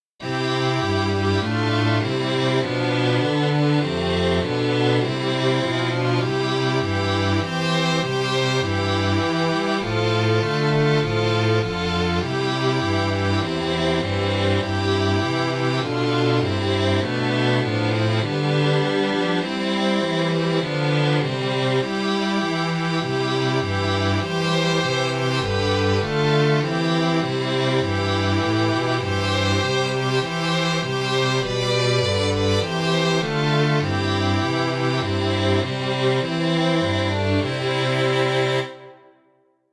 弦楽合奏